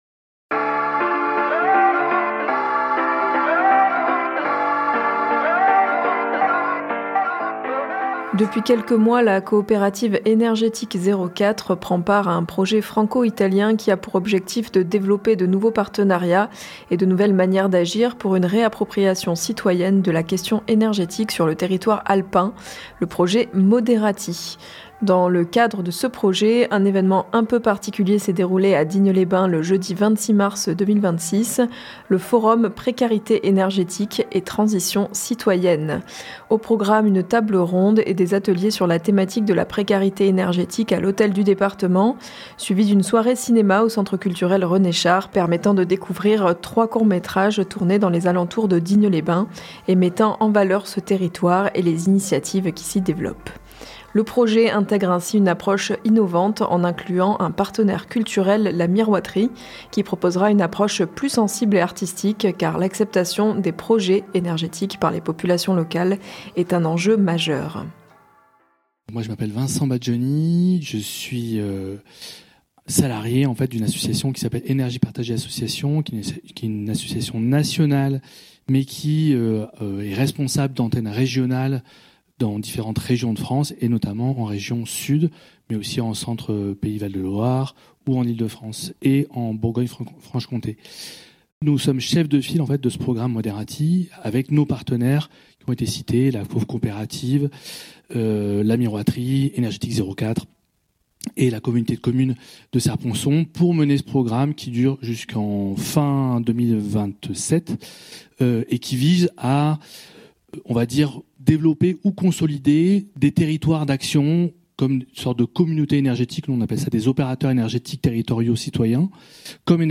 Reportage forum précarité énergétique - jeudi 26 mars 2026.mp3 (31.47 Mo)
Pour massifier la production d’énergies renouvelables, le projet franco-italien MODERATTI mise sur la modélisation d’un réseau franco-italien d’opérateurs transalpins d’énergie renouvelable citoyens et c'est dans le cadre de ce projet que s'est déroulé le forum précarité énergétique et transition citoyenne, à l'Hôtel du Département de Digne les Bains, jeudi 26 mars 2026. Au programme, une table ronde et des ateliers sur la thématique de la précarité énergétique, suivis d'une soirée cinéma au centre culturel René Char, permettant de découvrir 3 courts métrages tournés dans les alentours de Digne-les-Bains, et mettant en valeur ce territoire et les initiatives qui s'y développent.